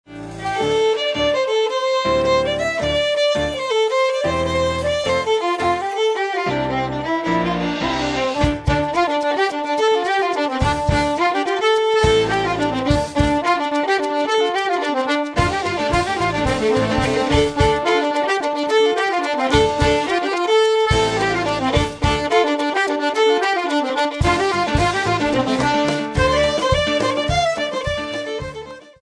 Listen to some excellent fiddle music.